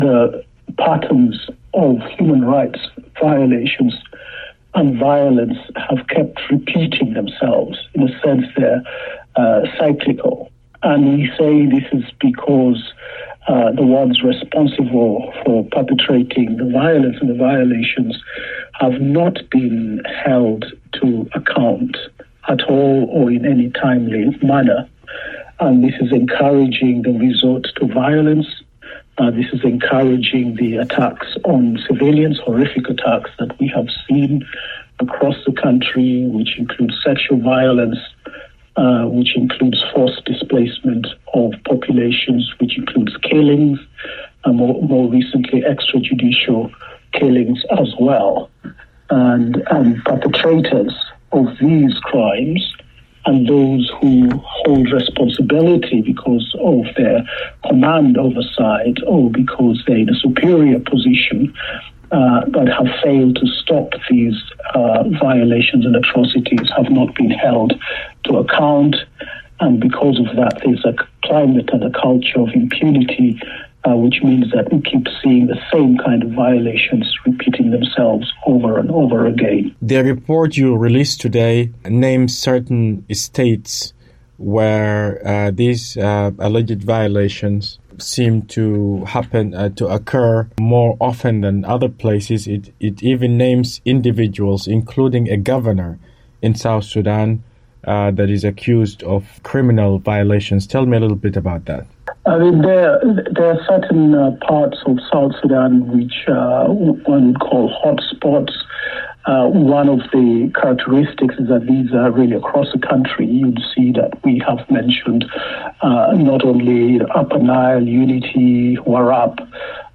The UN Human Rights Council has renewed the mandate of the Commission on Human Rights in South Sudan, following the release of its reporting alleging unabated violence and human rights violations, with little or no accountability. Barney Afako is a United Nations Human Rights Commissioner for South Sudan.